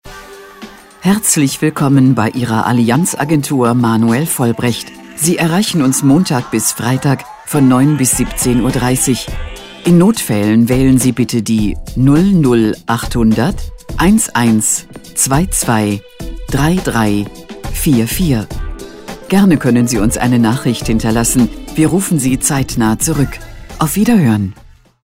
Anrufbeantworter Ansage
Anrufbeantworter-Ansage.mp3